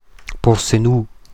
Ladinisch-mundartliche Form
[porseˈnu]
Gadertaler Variante.
Porsenù_Mundart.mp3